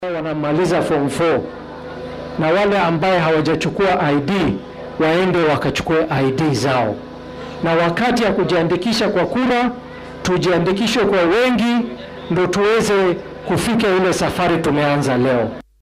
DHEGEYSO:Senetarka Garissa oo baaq u diray dhalinyarada